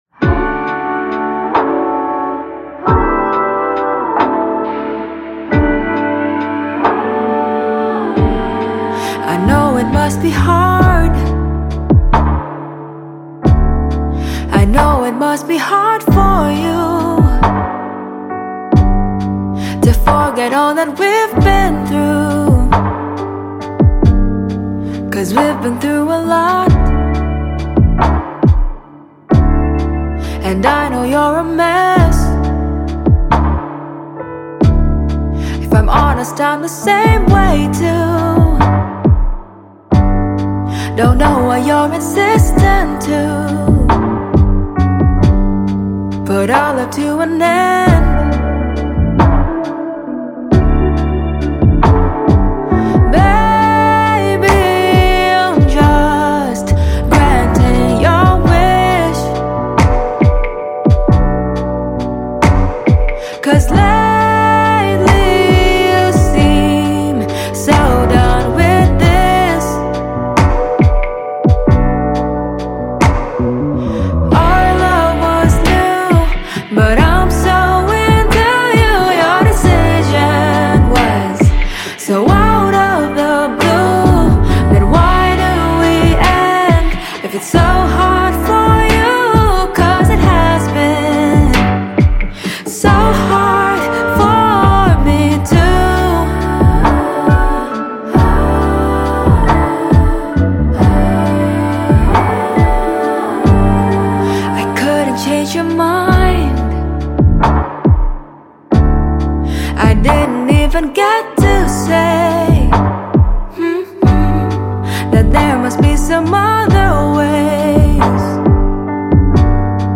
Surabaya Pop